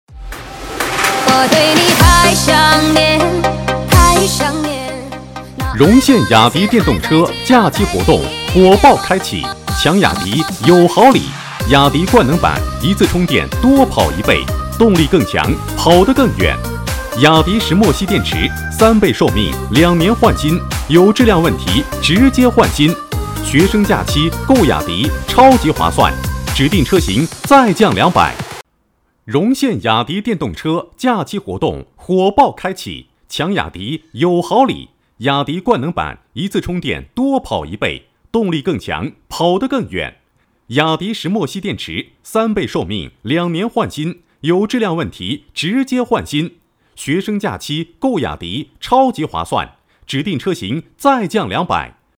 男32号配音师
7年从业经验，声音庄重、浑厚、大气。
代表作品 Nice voices 促销 广告 纪录片 朗诵 舌尖 新闻 专题片 促销-男32-电动车.mp3 复制链接 下载 促销-男32-珠宝店.mp3 复制链接 下载